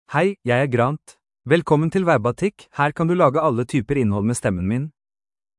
MaleNorwegian Bokmål (Norway)
Grant — Male Norwegian Bokmål AI voice
Voice sample
Male